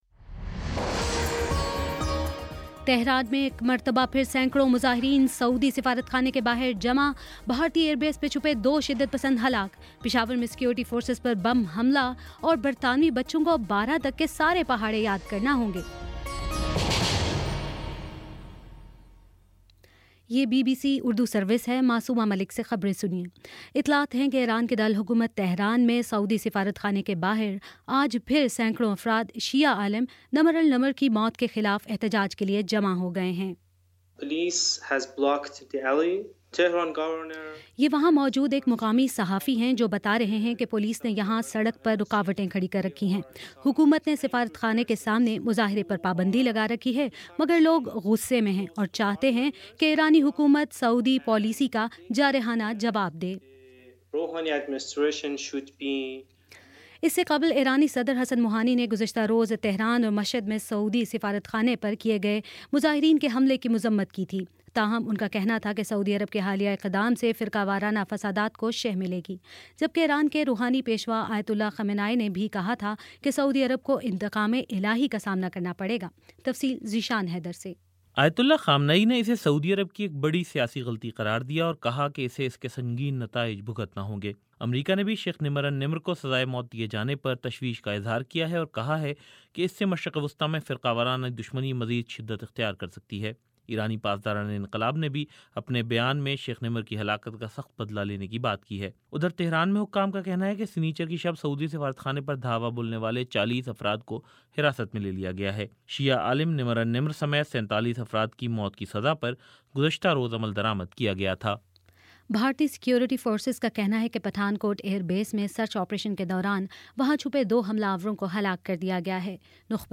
جنوری 03: شام سات بجے کا نیوز بُلیٹن